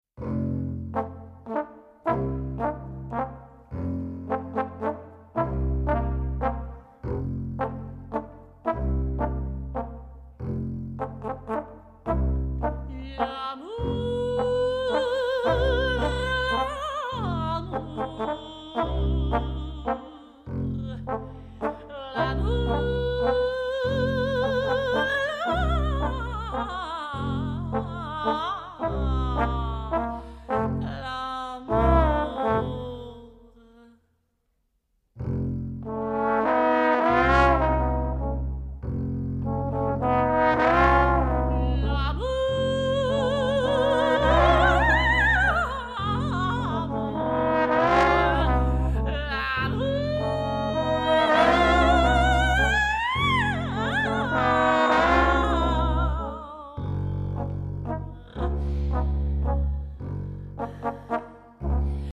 contrebasse
trombone